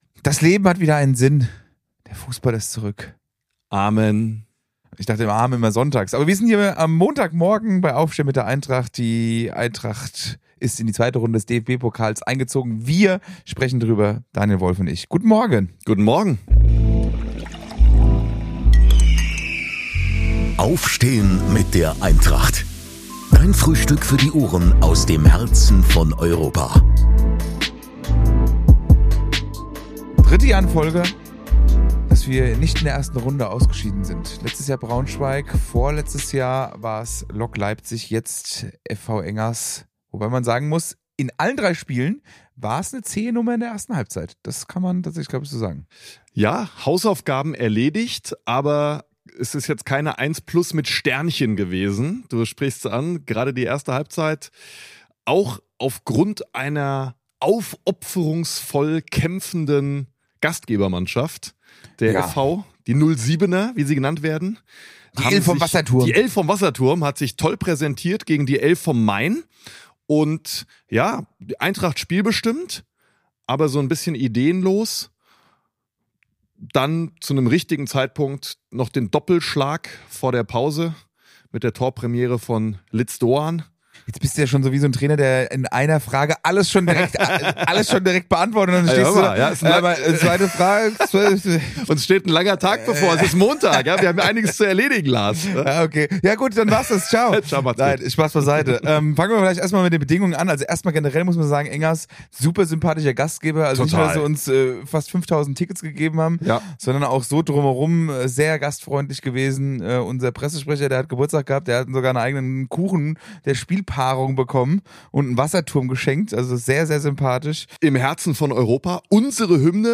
Die Eintracht besiegte den Oberligisten FV Engers 07 im DFB-Pokal am Ende souverän mit 5:0, musste aber jede Menge Geduld mitbringen. Im Blickpunkt der Partie: Neuzugang Ritsu Doan. Nachlese mit den EintrachtFM-Kommenatoren.